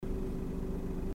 アイドリング時の車内の音声
音声の収録は車内の運転席（顔の位置）で収録していますよ。
ha36s-alto-normal_idling.mp3